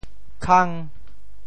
潮州府城POJ khâng